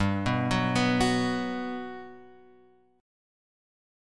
G Major 7th